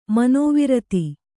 ♪ manōvirati